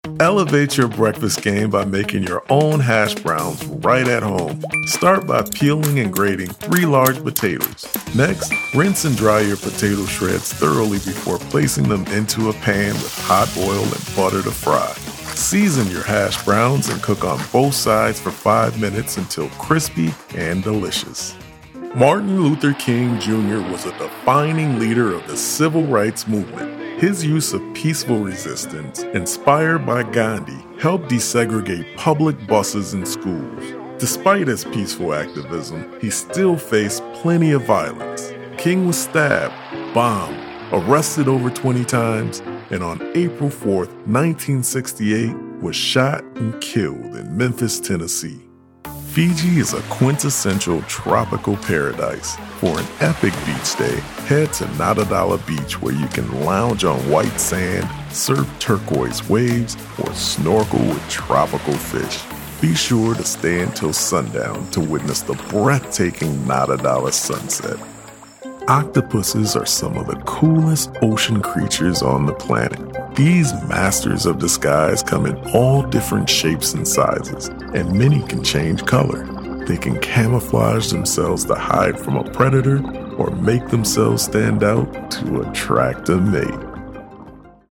A male voice artist with deep, rich, memorable voiceovers.
Narration Demos
English - Midwestern U.S. English
Middle Aged